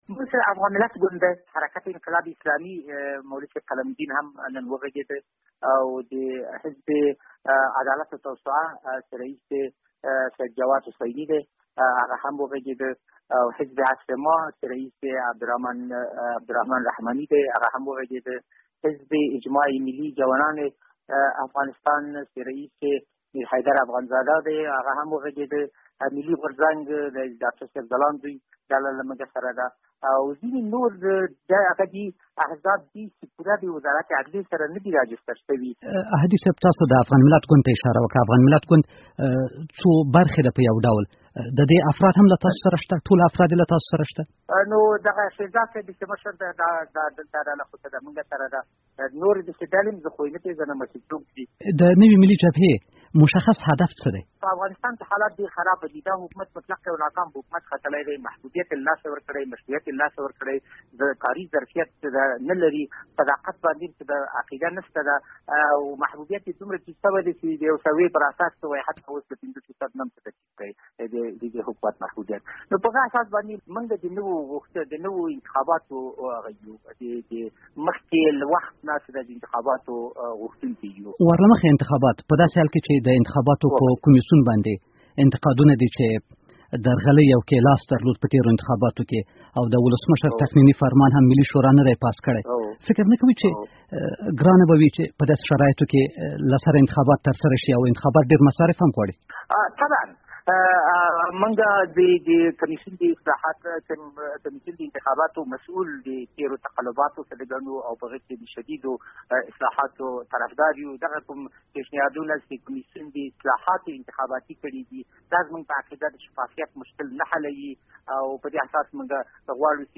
له ښاغلي انوارالحق احدي سره مرکه: